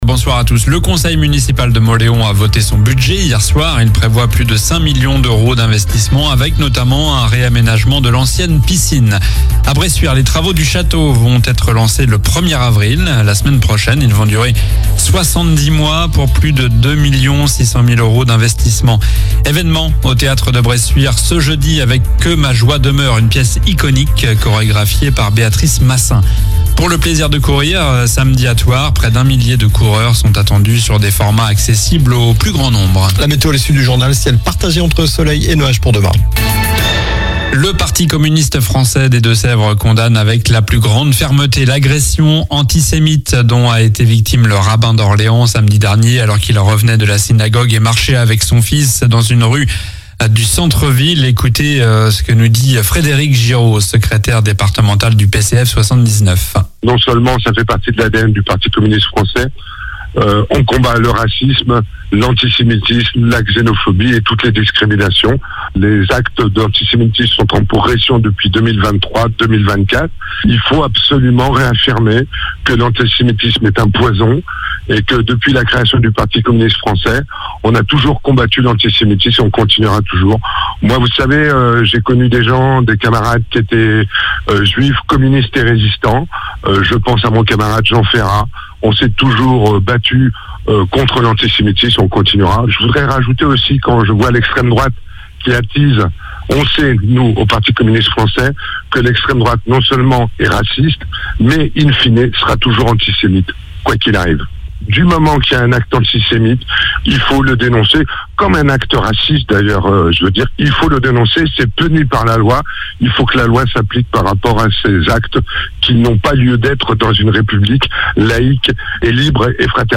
Journal du mardi 25 mars (soir)